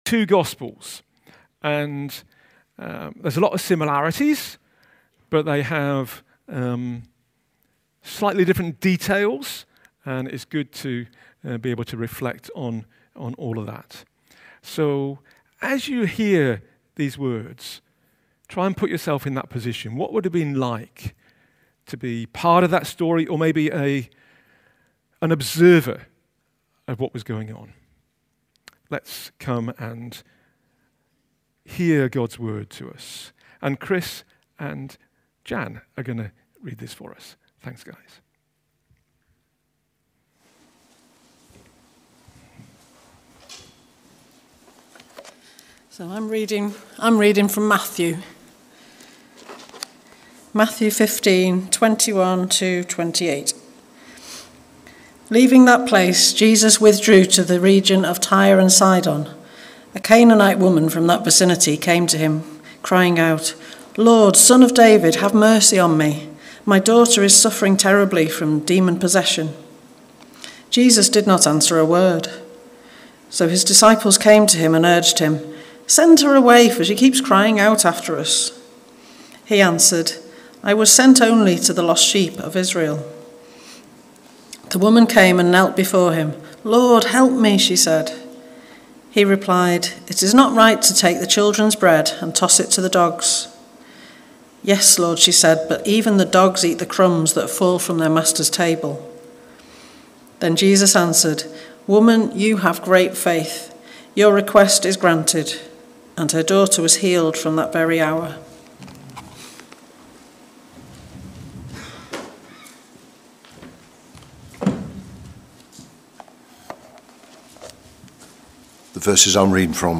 A talk from the series "Encounters with Jesus."